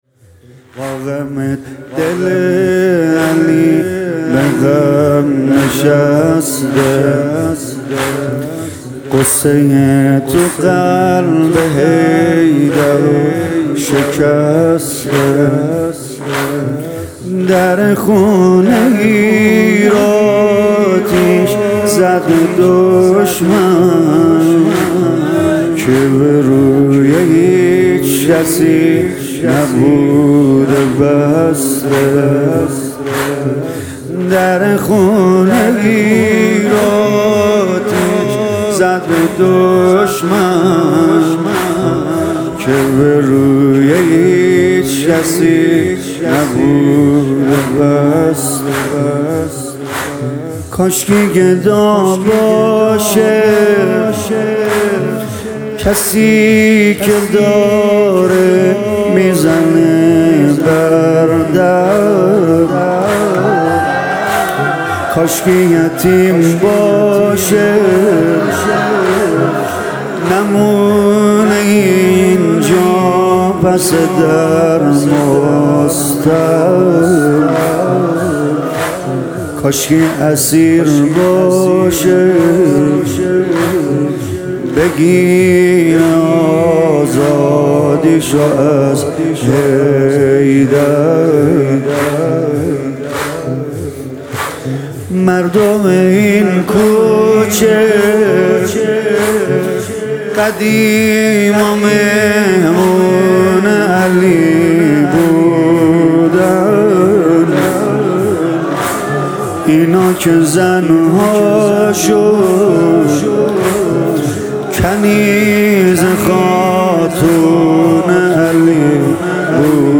متن سینه زنی زمینه دهه فاطمیه1401